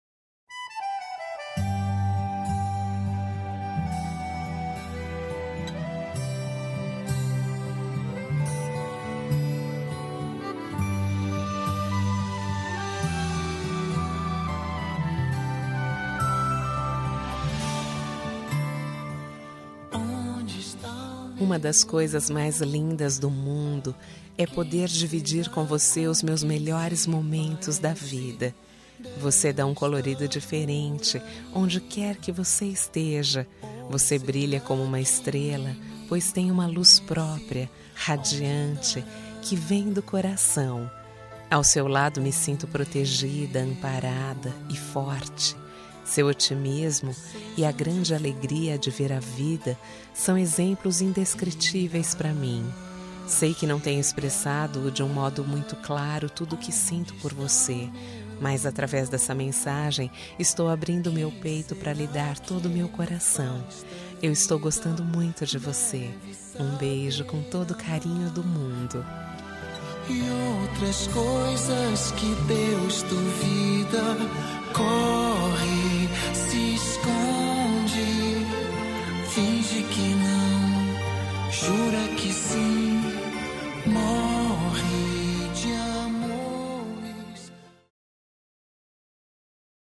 Telemensagem de Conquista – Voz Feminina – Cód: 140109